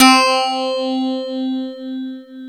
Index of /90_sSampleCDs/Club-50 - Foundations Roland/PNO_xFM Rhodes/PNO_xFM Rds C x2